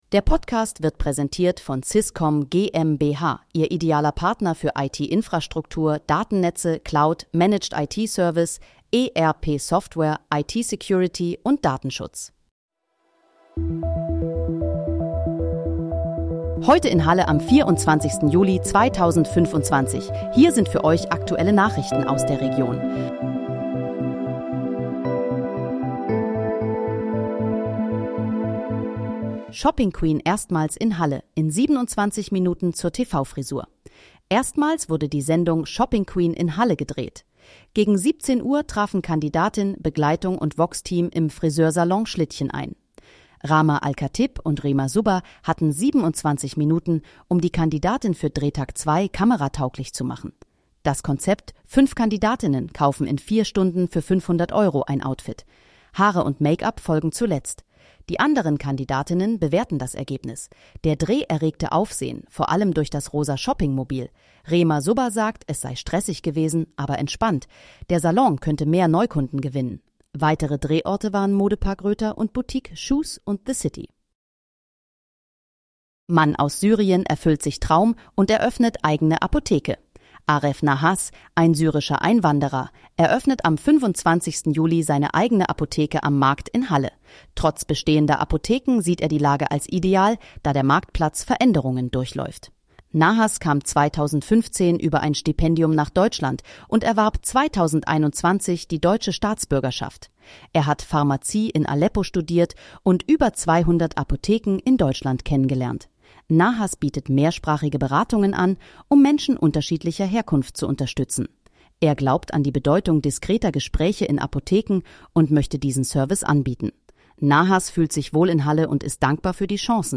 Heute in, Halle: Aktuelle Nachrichten vom 24.07.2025, erstellt mit KI-Unterstützung
Nachrichten